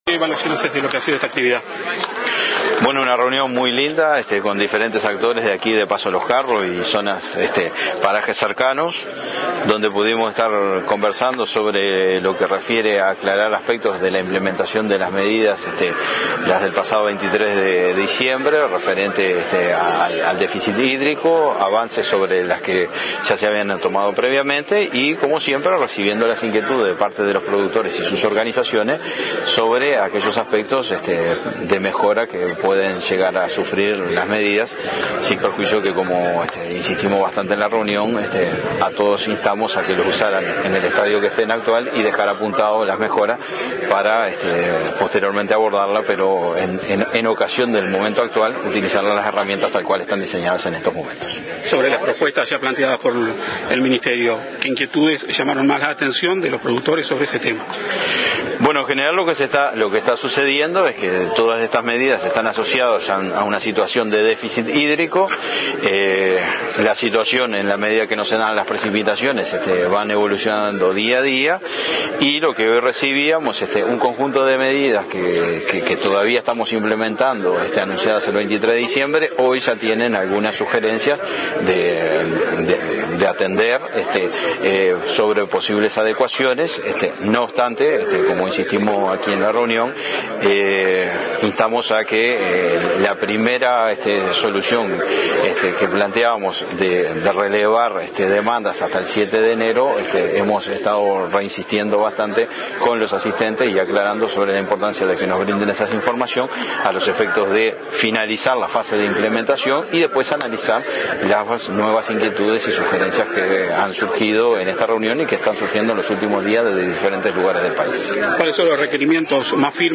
Declaraciones a la prensa del subsecretario de Ganadería, Daniel Garín, luego de la reunión mantenida en Paso de los Carros con productores